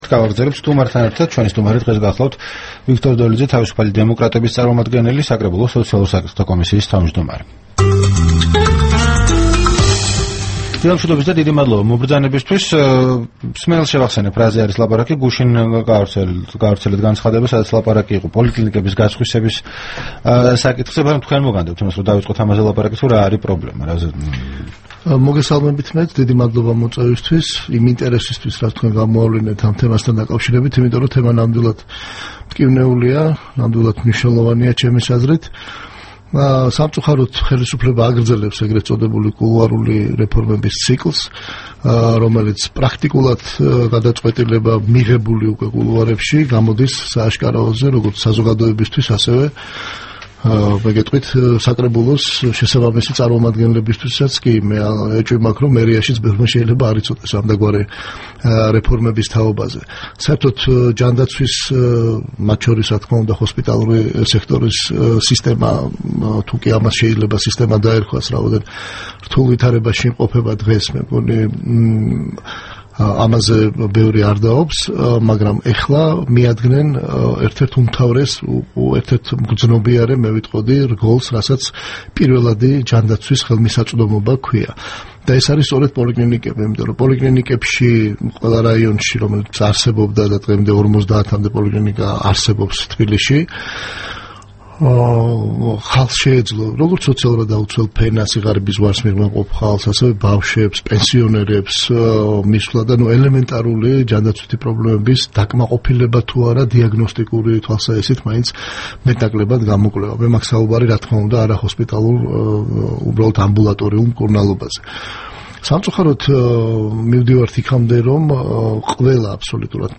რადიო თავისუფლების თბილისის სტუდიაში სტუმრად იყო საკრებულოს სოციალურ საკითხთა კომისიის თავმჯდომარე ვიქტორ დოლიძე.
საუბარი ვიქტორ დოლიძესთან